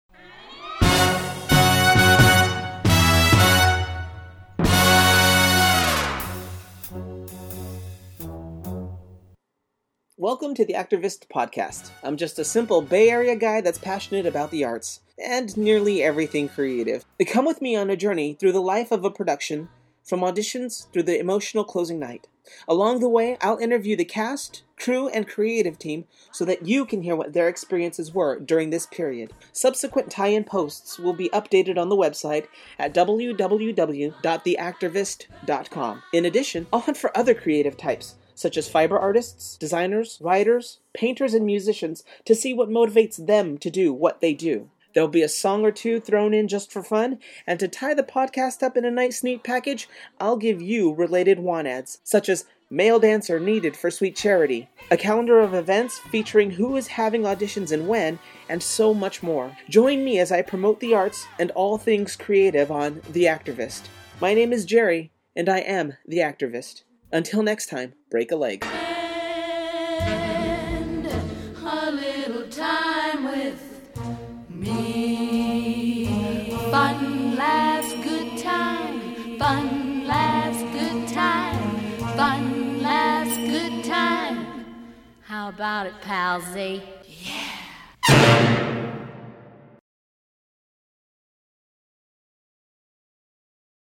You sounded very poised, very sure of yourself and what you are trying to do, and I think you’ll go far with this.